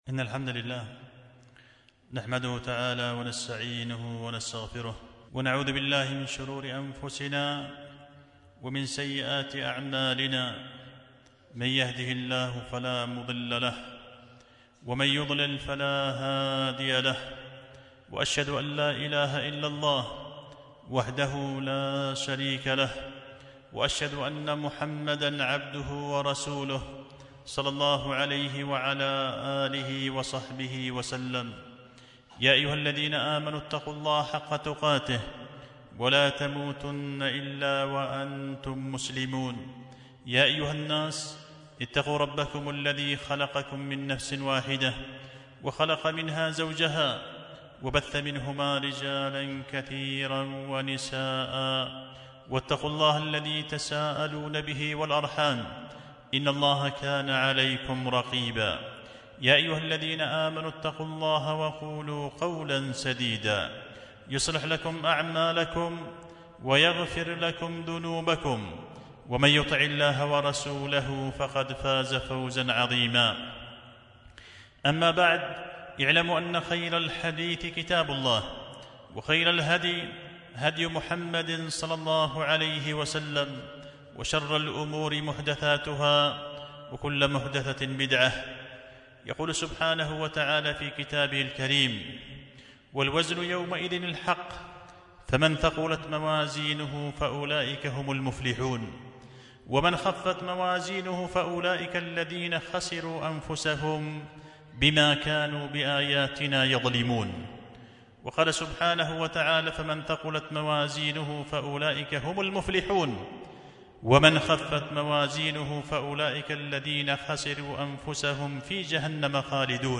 خطبة جمعة بعنوان بهجة القلوب وجلاء الأحزان في بيان بعض الأعمال المثقلة للميزان